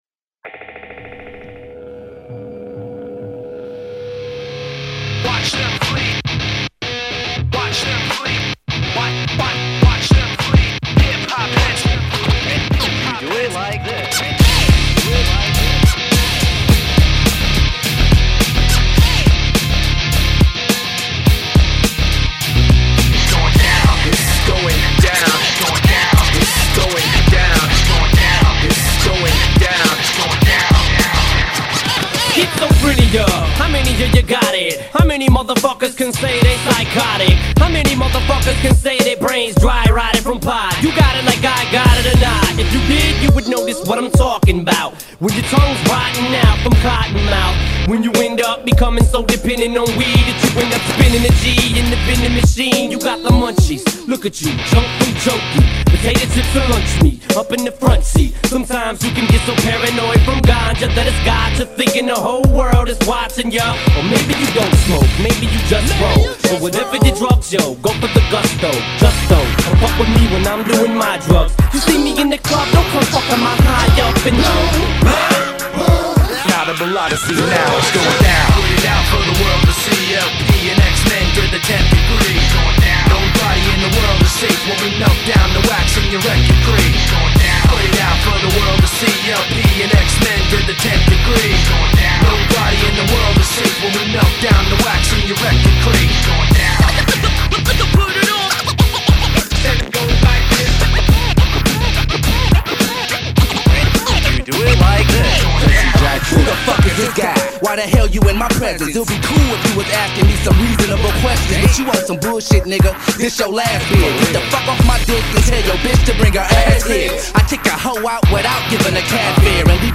rapcore
هیپ هاپ رپ متال